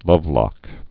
(lŭvlŏk)